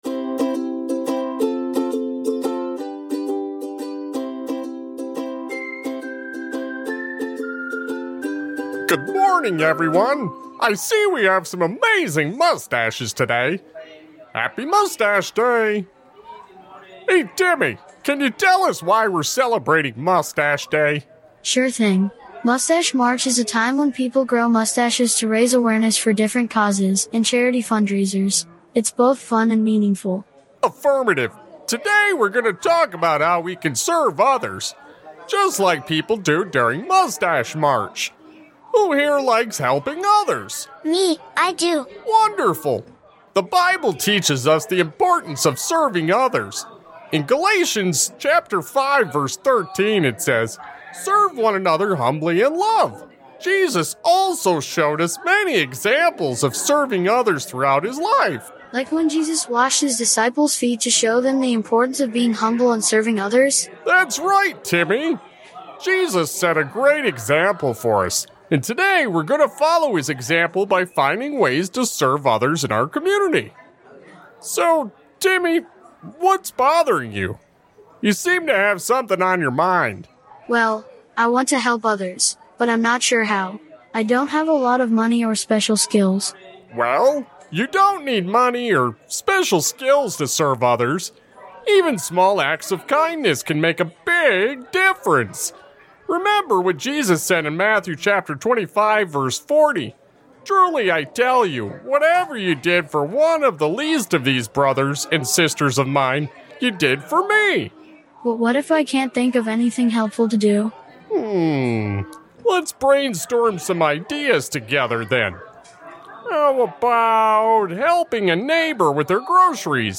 Little Lessons in Faith is a charming audio drama series for kids, offering bite-sized, faith-based stories.